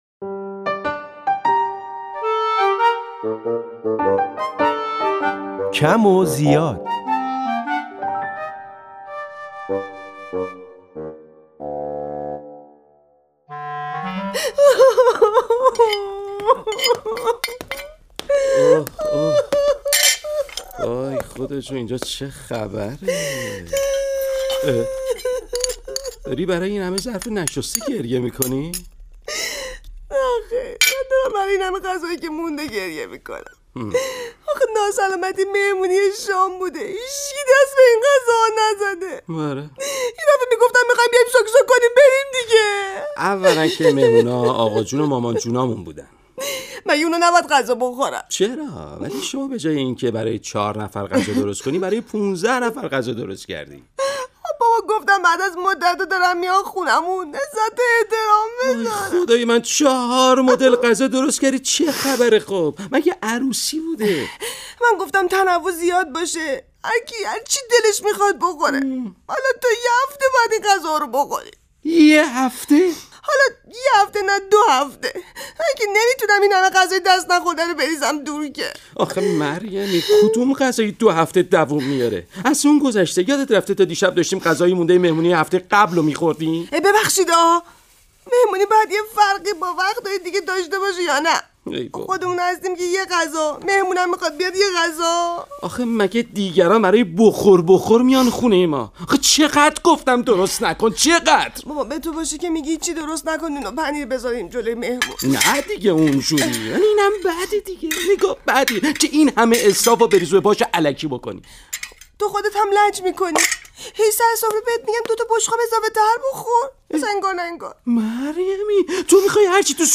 سه‌شنبه‌ها با رادیو نمایش